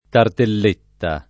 vai all'elenco alfabetico delle voci ingrandisci il carattere 100% rimpicciolisci il carattere stampa invia tramite posta elettronica codividi su Facebook tarteletta [ tartel % tta ] o tartelletta [ tartell % tta ] s. f. (gastron.)